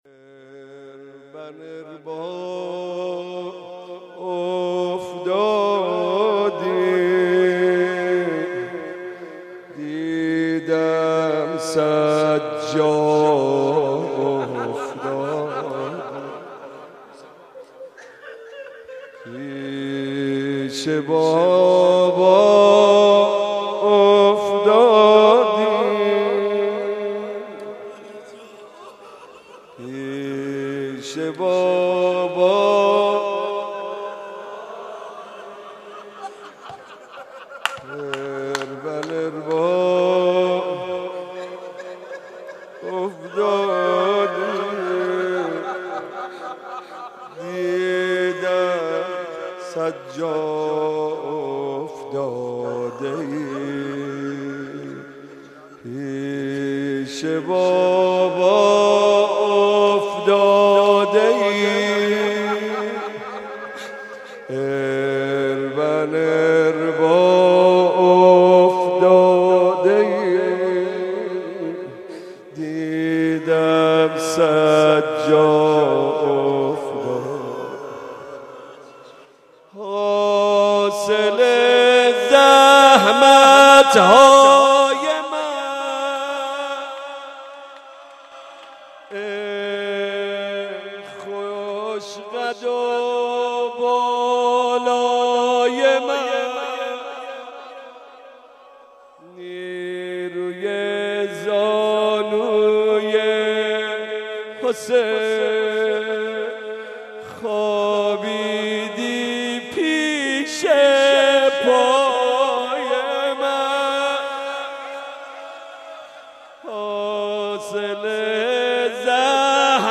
پیر کربلا بی عصا شده دیگه (زمینه زیبا)
شب هشتم محرم93
شور جدید
نوحه